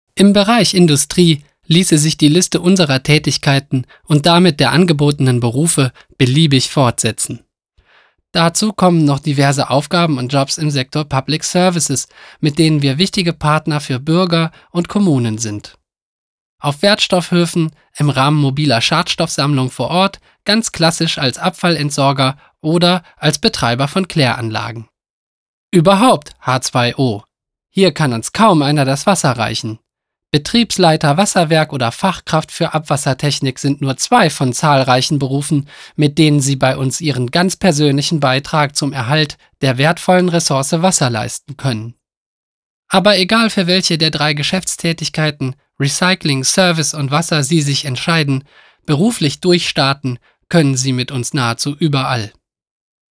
dynamic - professional - creative
deutsch
Sprechprobe: Industrie (Muttersprache):